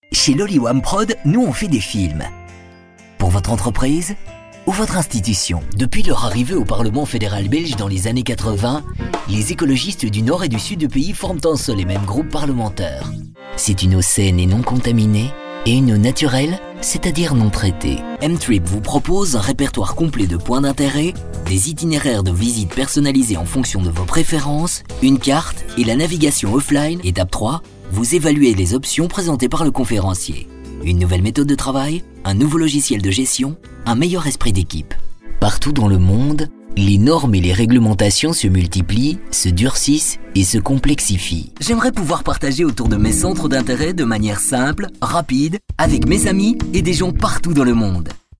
Sprechprobe: Industrie (Muttersprache):
Stunning medium voice. Young and dynamic